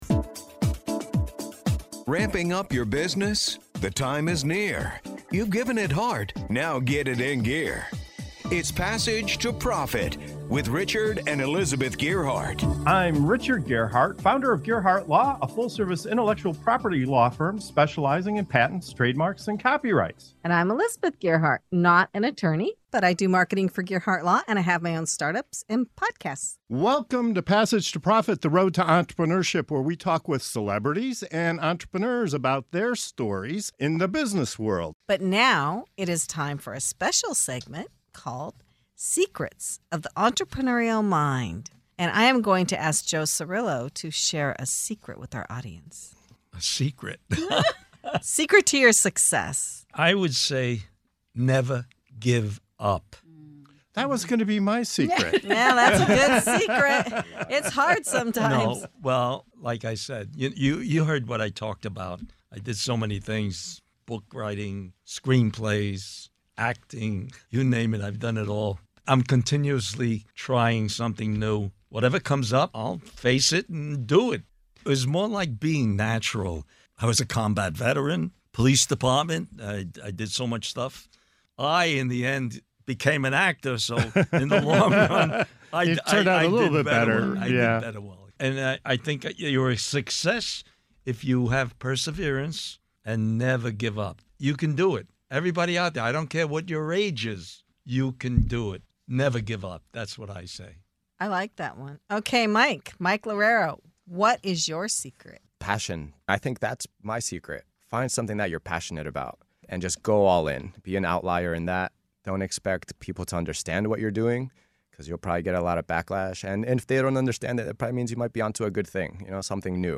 In this segment of "Secrets of the Entrepreneurial Mind" on Passage to Profit Show, top innovators share their keys to success. From the power of perseverance and passion to the magic of storytelling and teamwork, our guests reveal what drives them to push boundaries and thrive.